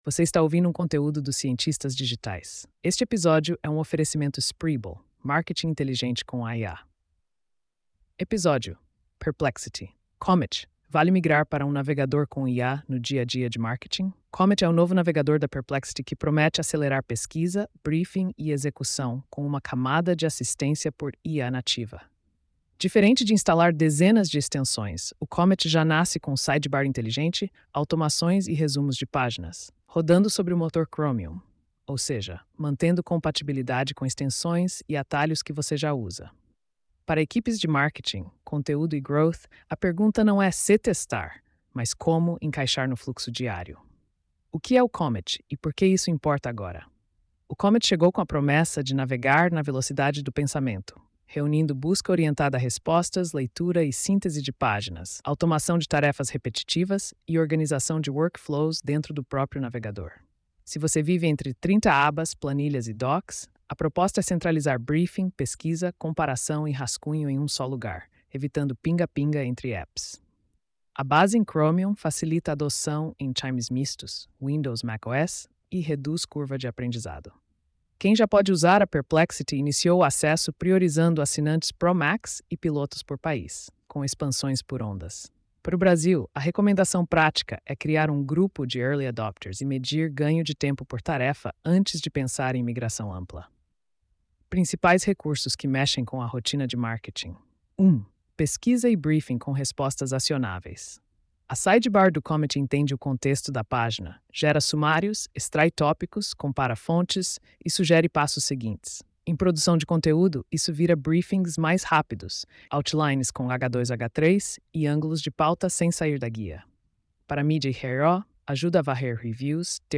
post-4437-tts.mp3